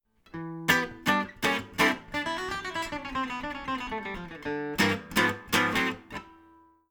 combine chords and single string fills over the four bar chord progression: Am/// D7/// G/// G///